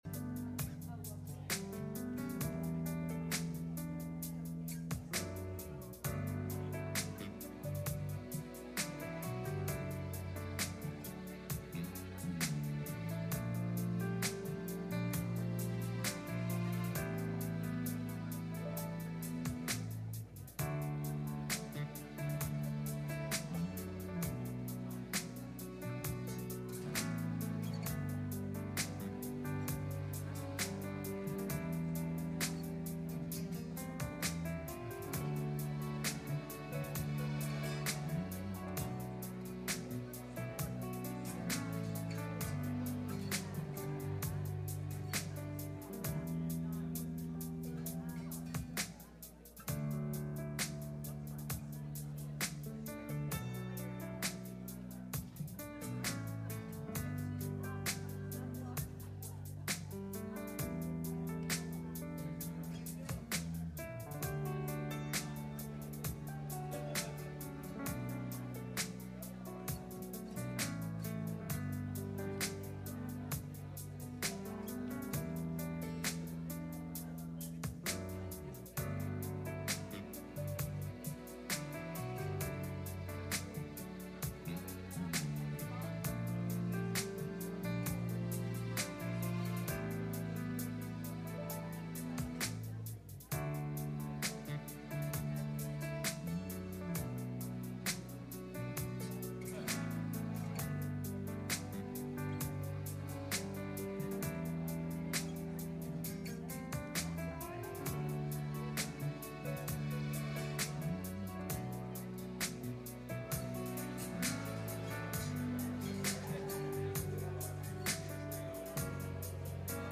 Youth Service
Service Type: Sunday Morning